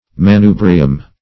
Manubrium \Ma*nu"bri*um\, n.; pl. L. Manubria, E.